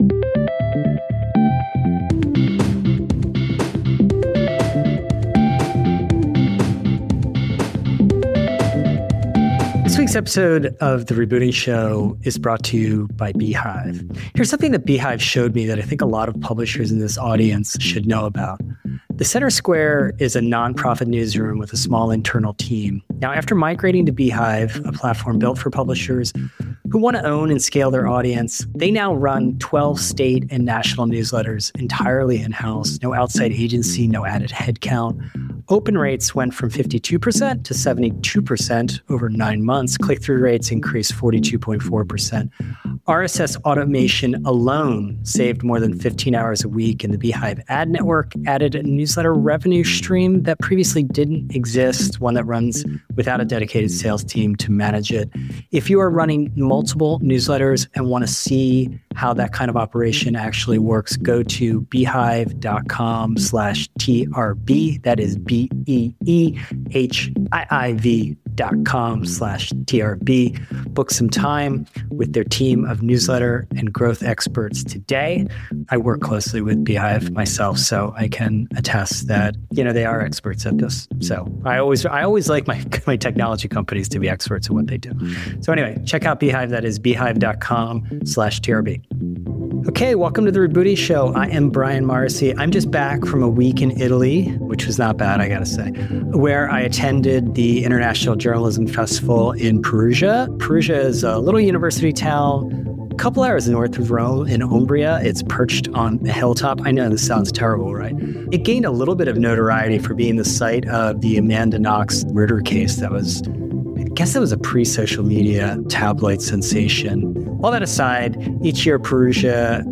In this live recording from the International Journalism Festival in Perugia, Italy